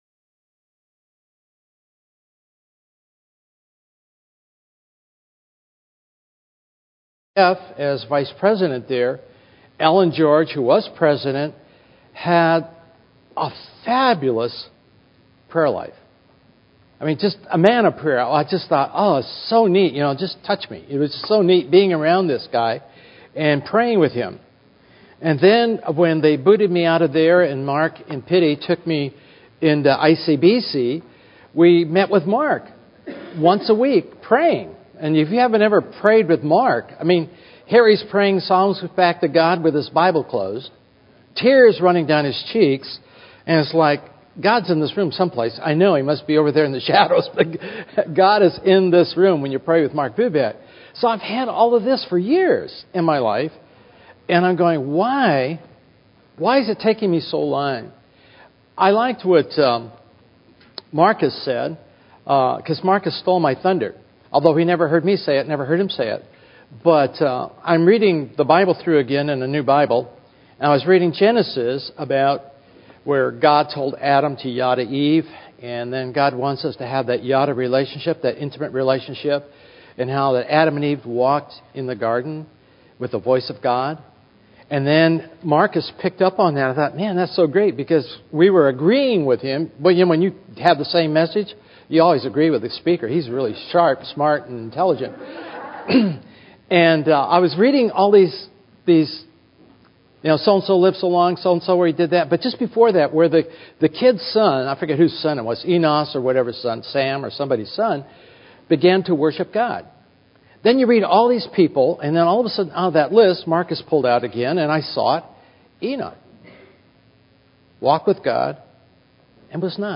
In this sermon, the speaker discusses the topic of temptation and how it affects every individual differently. He emphasizes the importance of taking control of our thoughts before they lead to sinful actions.